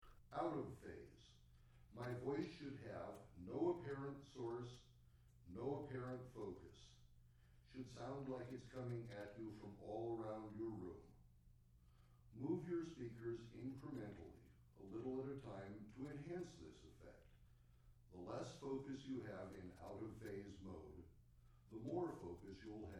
1. Channel Identification (Left / Right)  左右聲道檢查
2. Voice In Phase  正相人聲